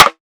Snares
SNARE.47.NEPT.wav